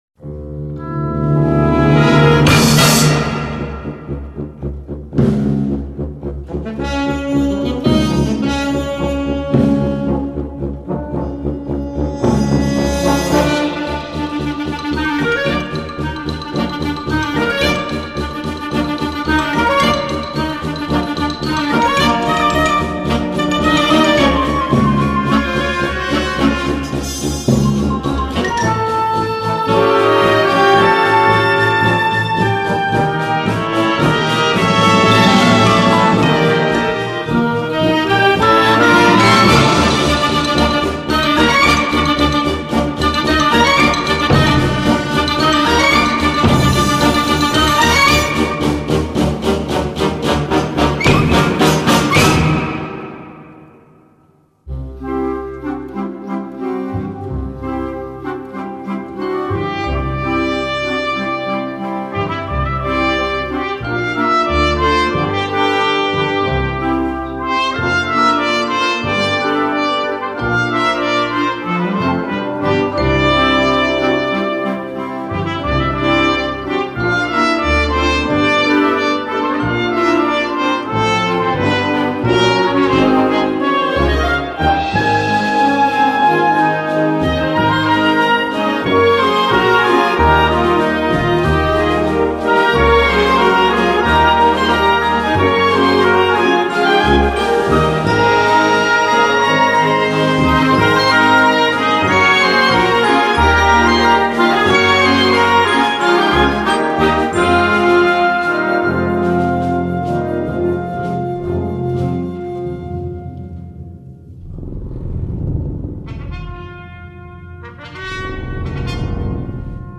Sheet Music for Concert Band